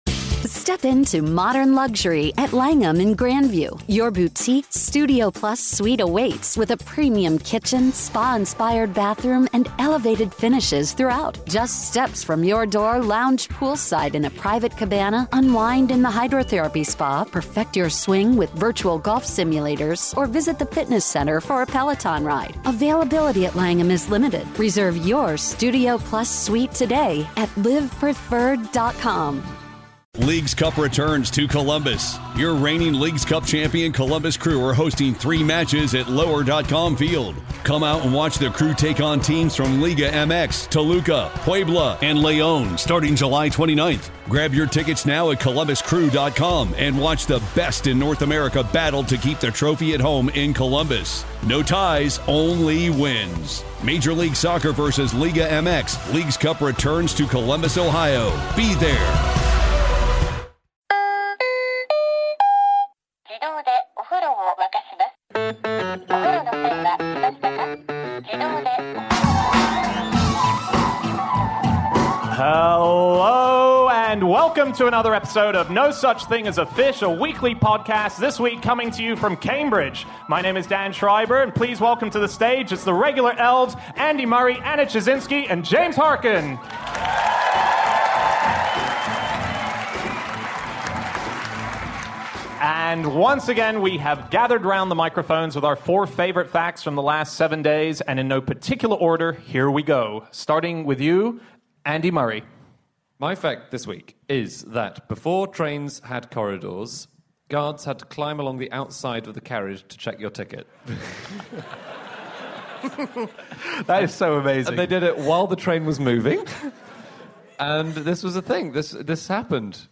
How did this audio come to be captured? Live from The Junction in Cambridge